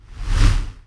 spell-whoosh-4.ogg